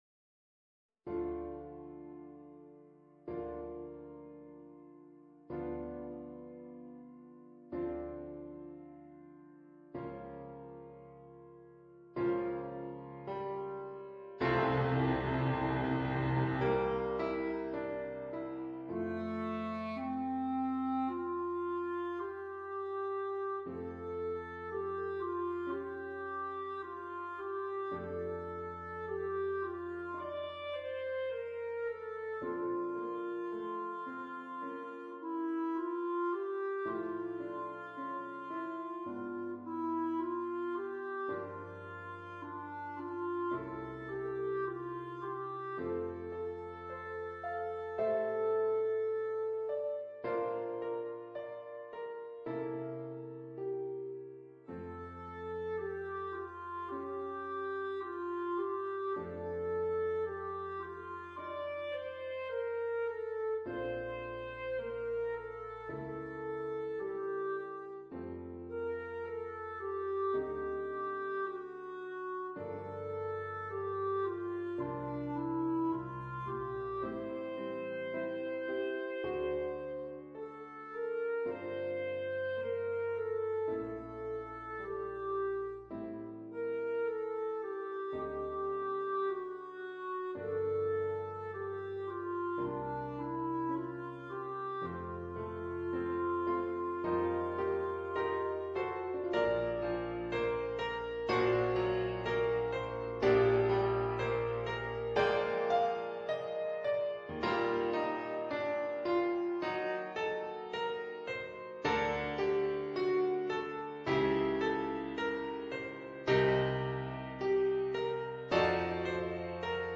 per clarinetto e pianoforte